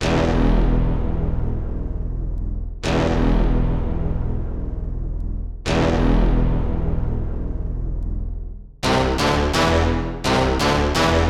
喇叭圈
描述：我创建的一个喇叭循环，我相信这可以被翻转。
标签： 85 bpm Hip Hop Loops Brass Loops 1.90 MB wav Key : D
声道立体声